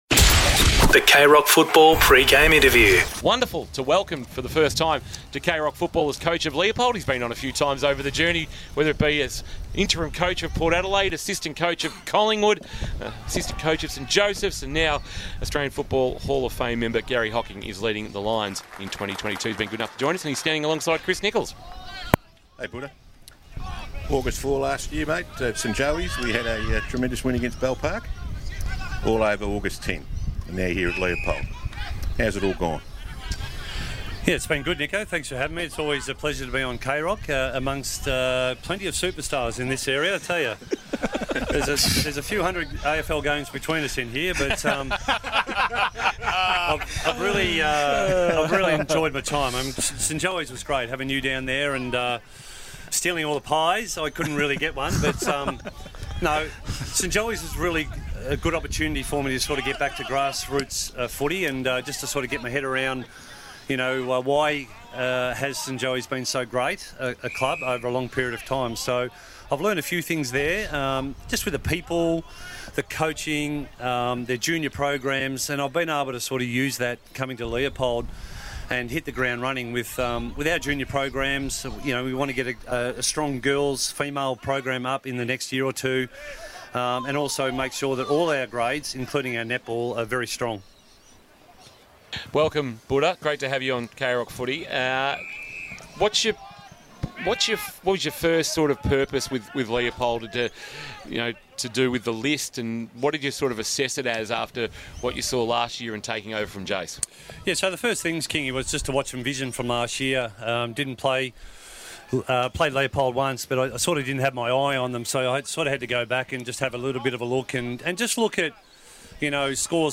2022 - GFL ROUND 1 - LEOPOLD vs. GEELONG WEST GIANTS: Pre-match Interview - Garry Hocking (Leopold coach)